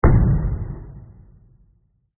explosion1.mp3